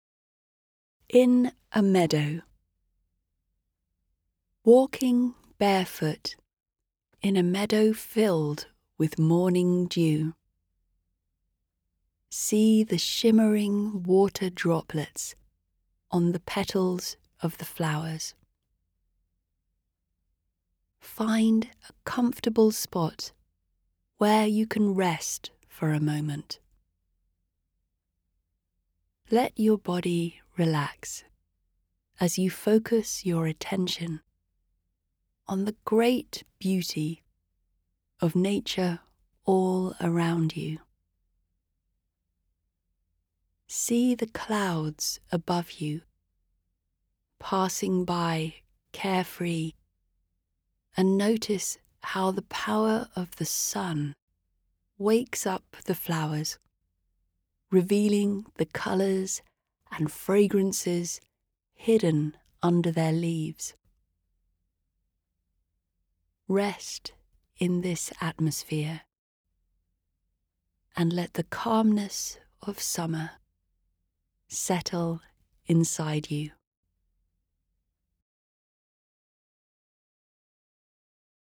Audiobook (British English)
Audiobook sample (mp3)